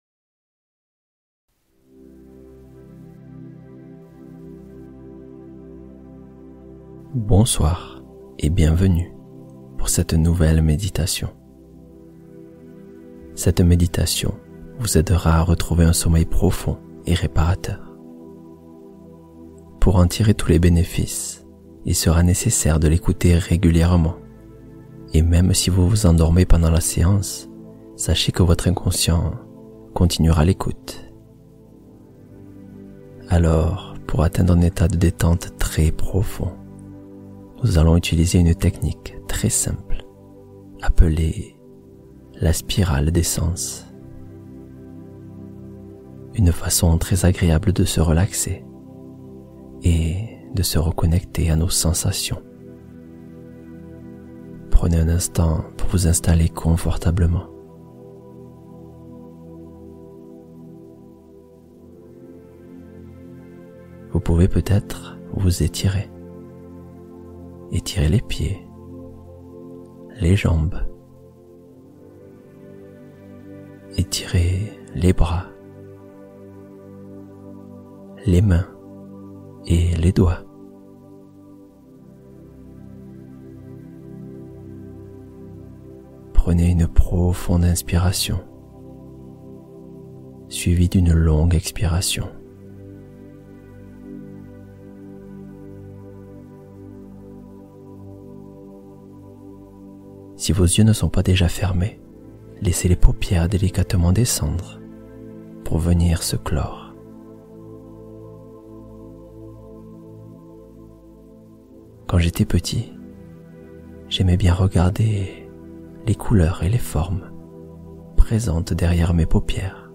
Voyage Inconscient : Méditation pour réparer la qualité de ses nuits